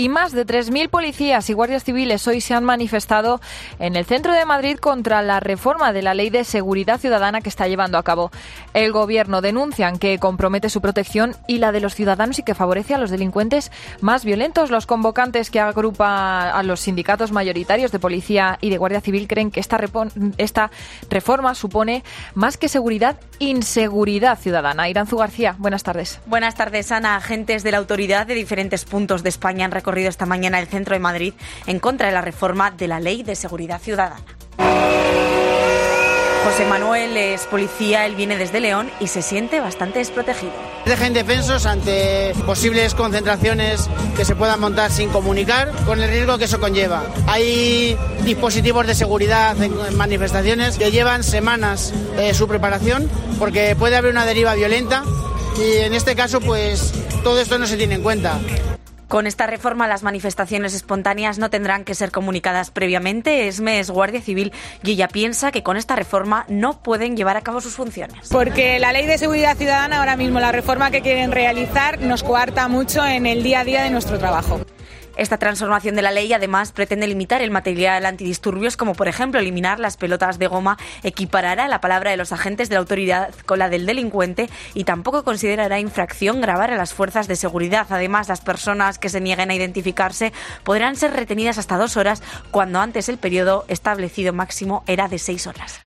Más de 3.000 policías y guardias civiles se han manifestado hoy en el centro de Madrid contra la reforma de la mal llamada 'Ley Mordaza' y en COPE hablamos con ellos
Hasta ahí se ha trasladado una compañera de COPE, que ha narrado y ha dado cuenta de cómo ha transcurrido esta mañana la manifestación, y ha podido hablar con algunos de los representantes de la manifestación y con los propios manifestantes, que expresaban su malestar por esta reforma.